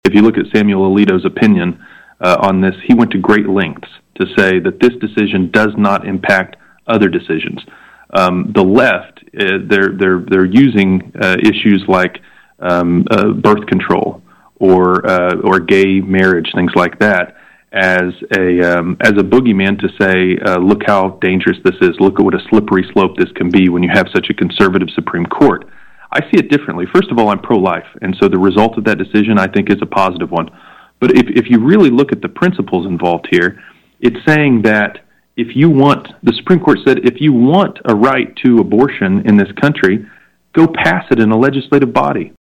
LaTurner joined KVOE’s Morning Show on Wednesday, in part to introduce himself to residents in Lyon, Chase, Morris and Wabaunsee counties and in part to talk about issues like abortion rights, civil rights and inflation impact. LaTurner applauded the recent US Supreme Court decision reversing Roe v Wade, and he says concerns from residents and lawmakers about diminishing women’s rights — and a spillover into diminishing civil rights as a whole — are unfounded.